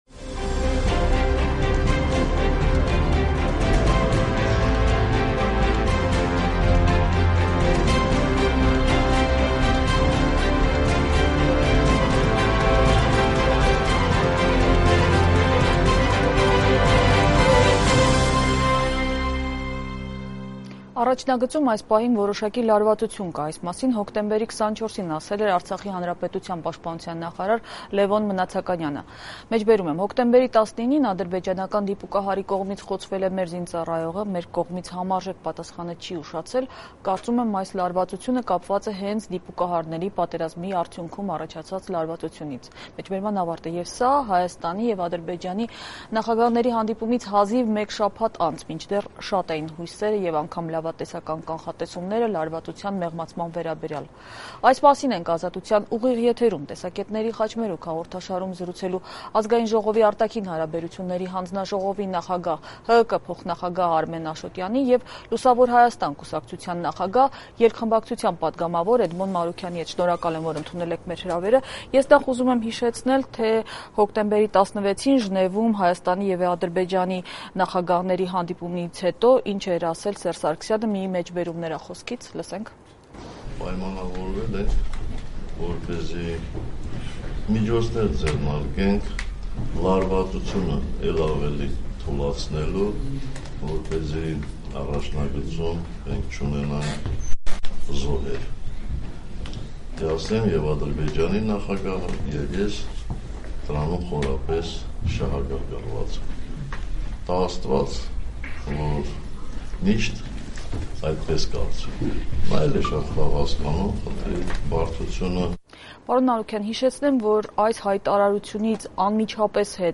Ի՞նչ տվեց Սարգսյան-Ալիև ժնևյան հանդիպումը, առաջընթա՞ց էր դա, թե հետընթաց․ Քննարկում «Ազատության» տաղավարում